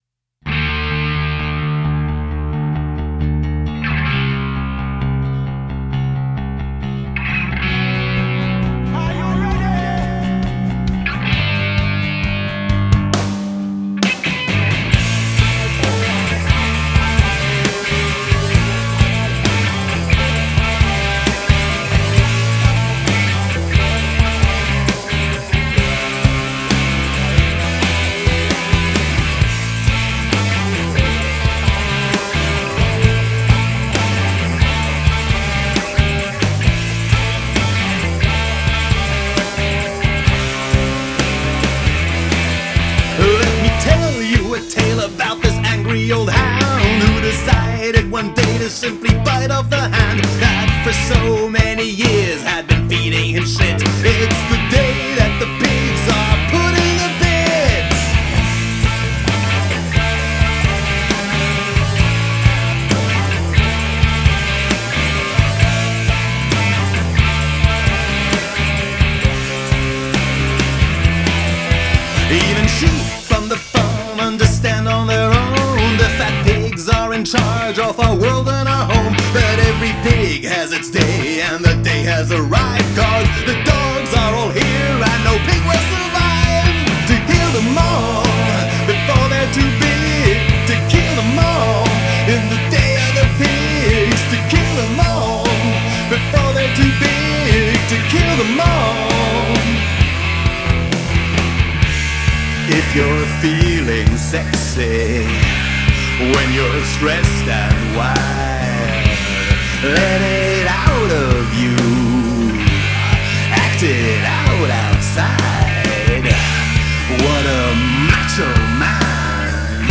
Catching up on global news made me want to re-post a song I recorded 2 years ago with my old band-mates